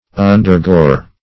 Undergore \Un`der*gore"\